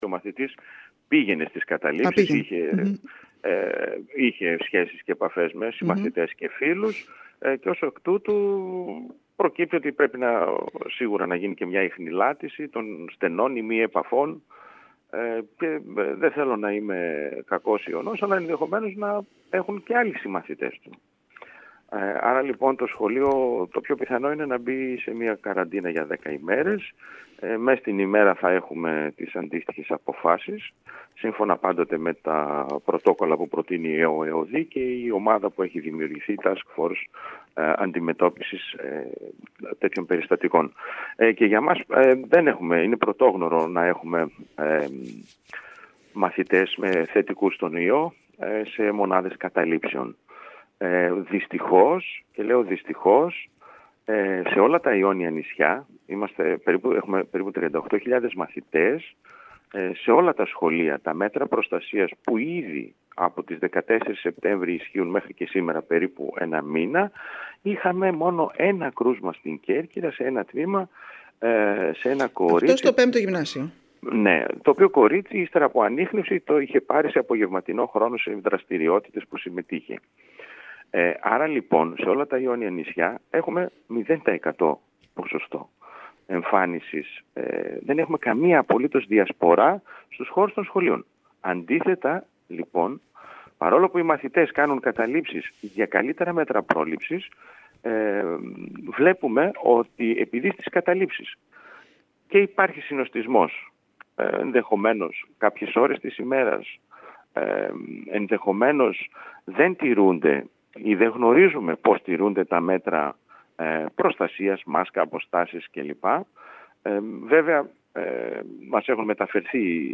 Μιλώντας στην ΕΡΤ Κέρκυρας ο περιφερειακός διευθυντής Εκπαίδευσης, Πέτρος Αγγελόπουλος, επισήμανε ότι το σχολείο είναι πιθανό να κλείσει για δέκα ημέρες, ενώ συνεχίζεται η ιχνηλάτηση για τις στενές επαφές του μαθητή.